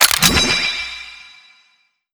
Active_reload_success.wav